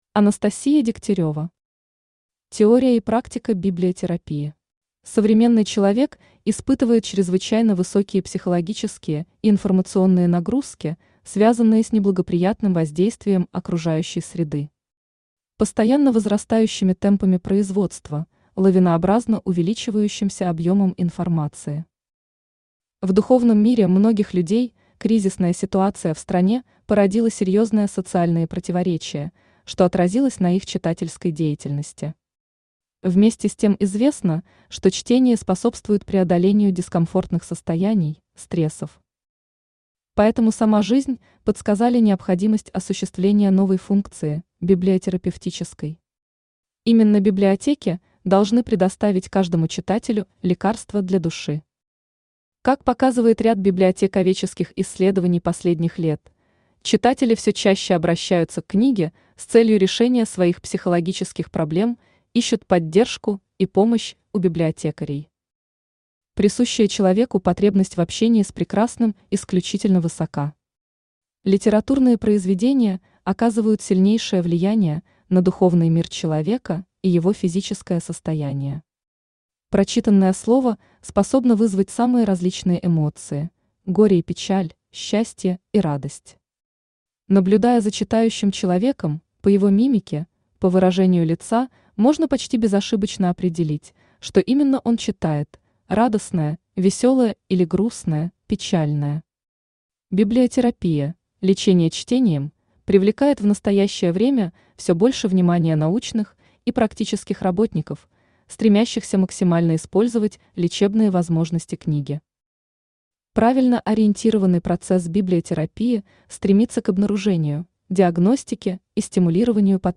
Aудиокнига Теория и практика библиотерапии Автор Анастасия Александровна Дегтярева Читает аудиокнигу Авточтец ЛитРес.